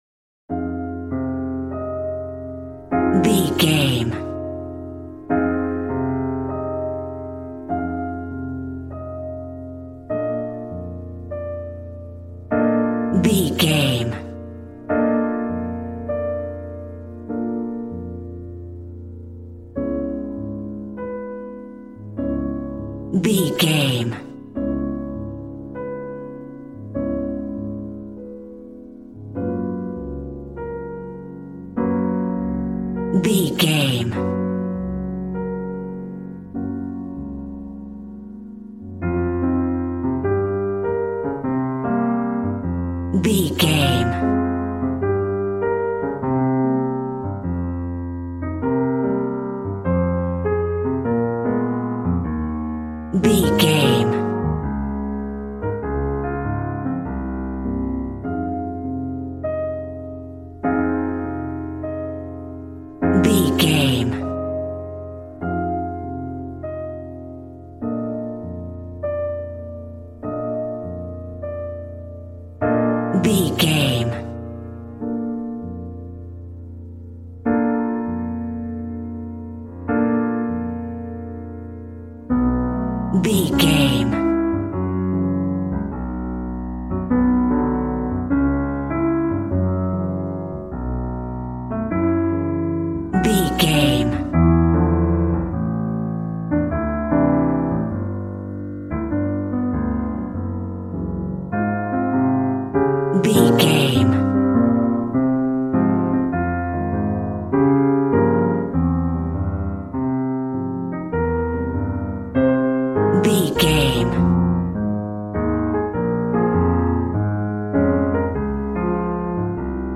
Ionian/Major
B♭
smooth
piano
drums